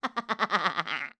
bruja_risa.wav